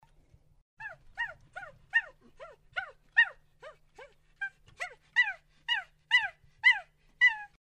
Звук маленькой обезьянки